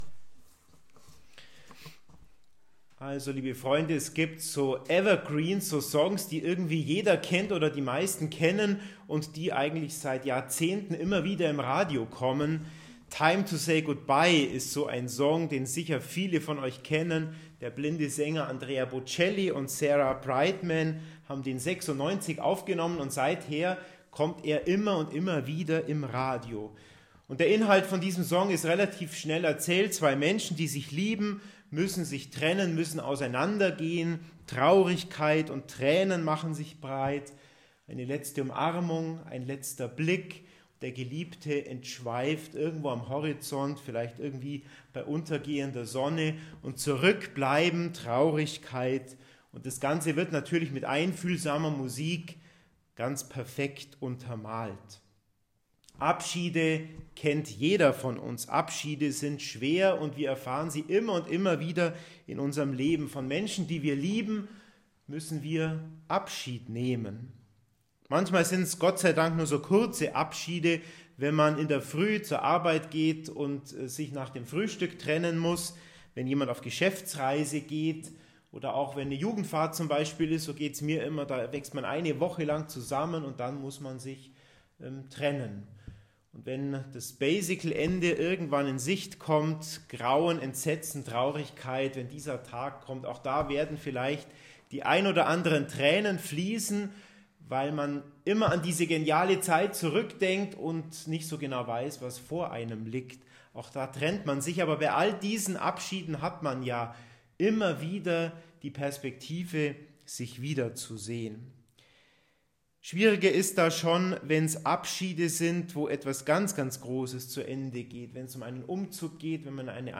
Homeily_Himmelfahrt.mp3